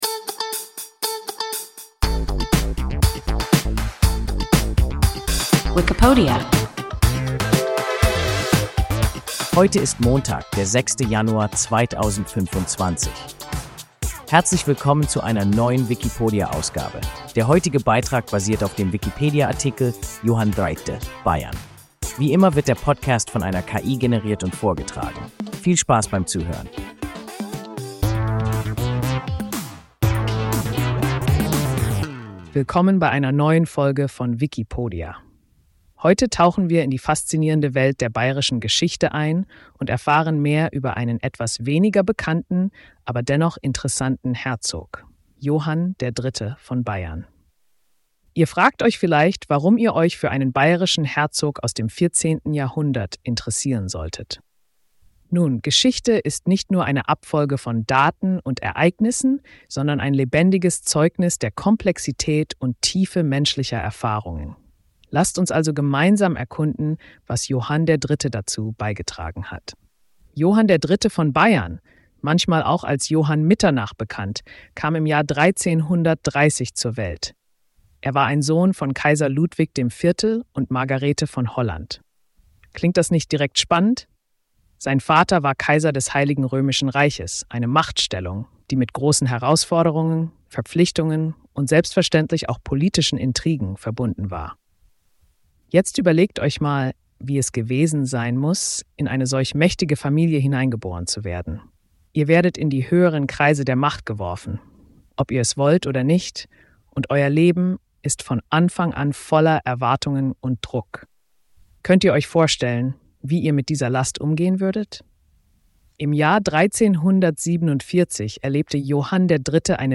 (Bayern) – WIKIPODIA – ein KI Podcast